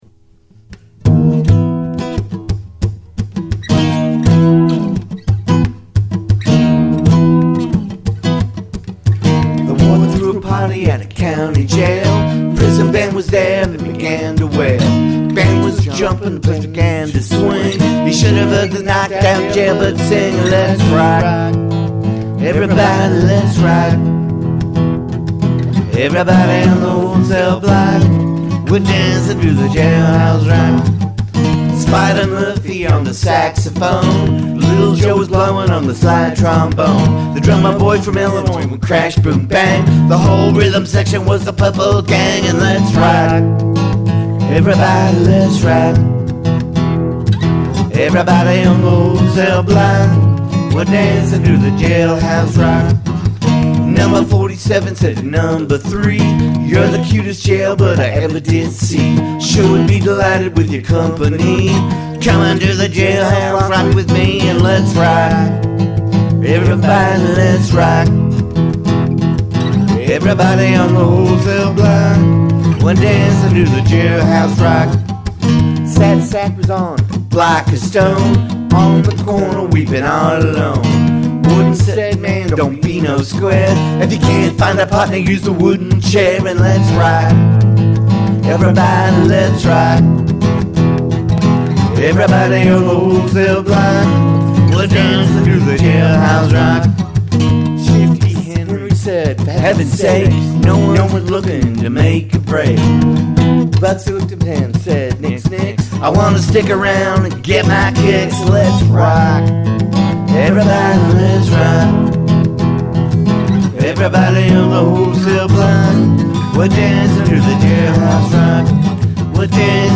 acoustic hillbillie rap cover